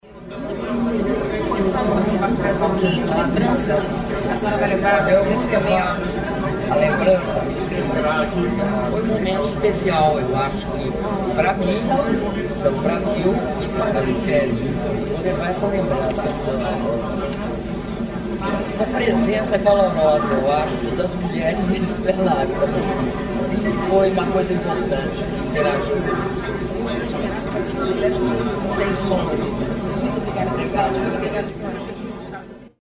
Entrevista exclusiva concedida pela Presidenta da República, Dilma Rousseff, à Rádio ONU - Nova Iorque/EUA